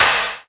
Percussion #11.wav